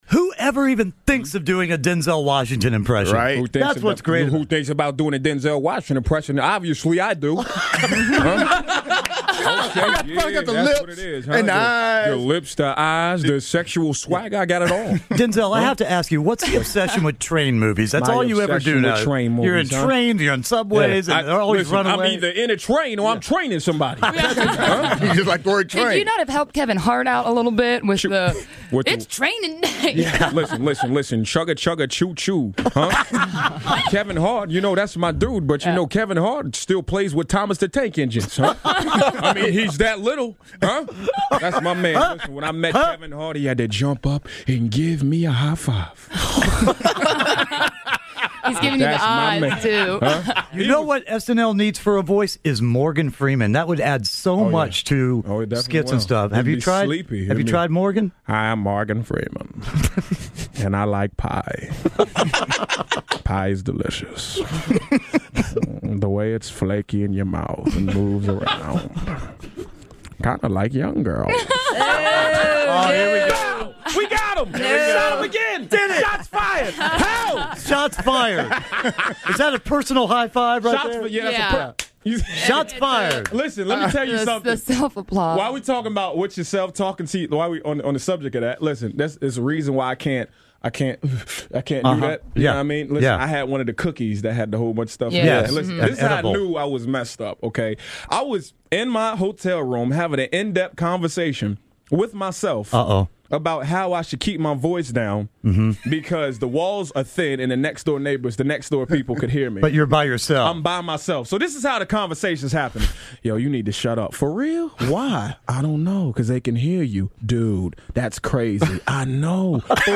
Jay Pharoah does the best celebrity impressions. Watch has he impersonates Jay-Z, Christopher Walken, Chris Rock, Kanye West and Denzel Washington!